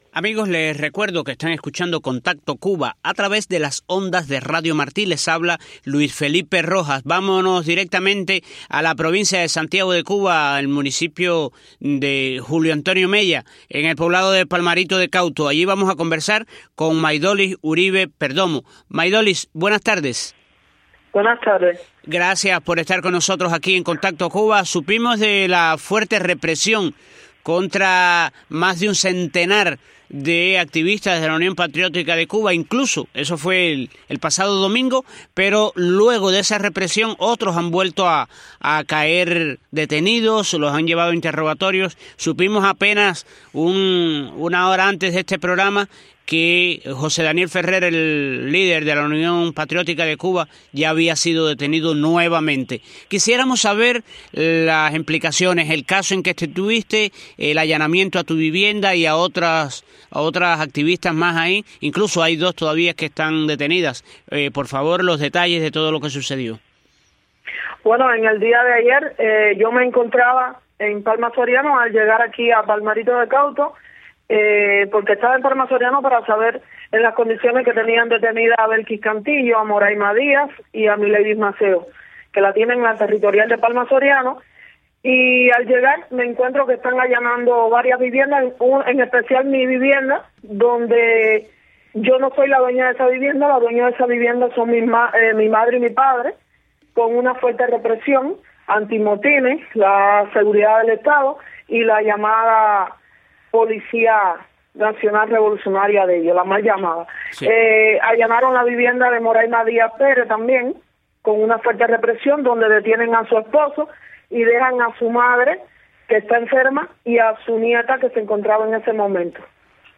explicó en entrevista para el programa Contacto Cuba, de Radio Martí.